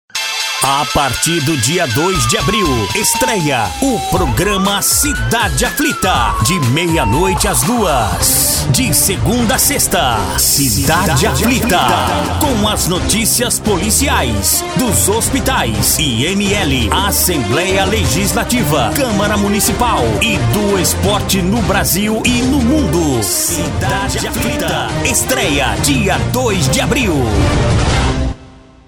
IMPACTO: